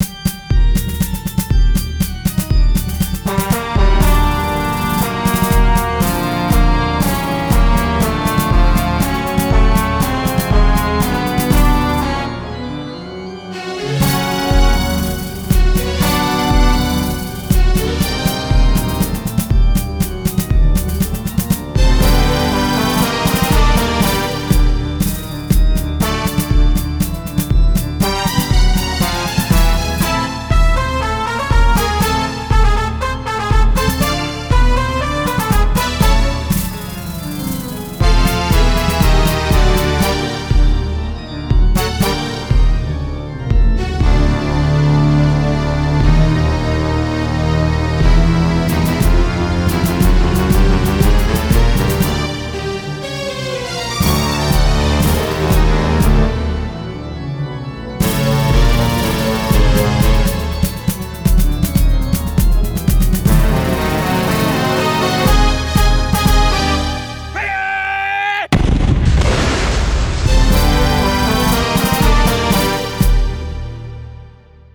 Resumo: Trilha sonora criada para a fase "Pirata"